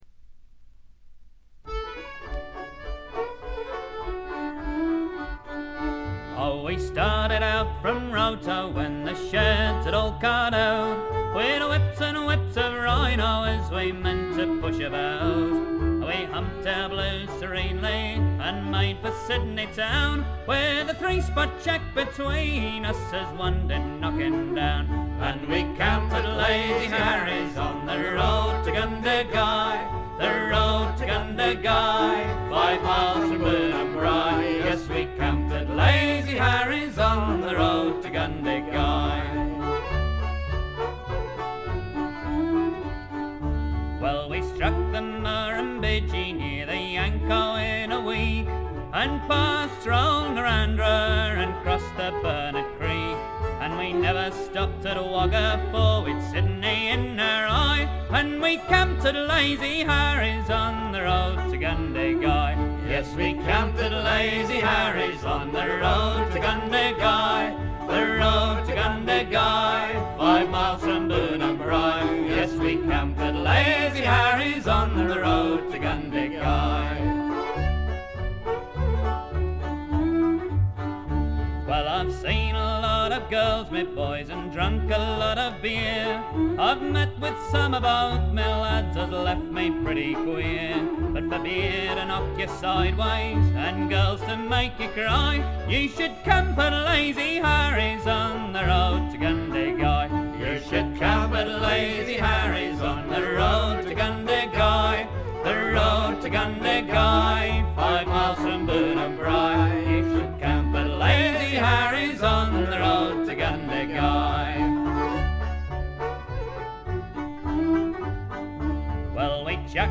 これ以外はところどころで軽微なノイズ感を感じる程度。
トラッド基本盤。
試聴曲は現品からの取り込み音源です。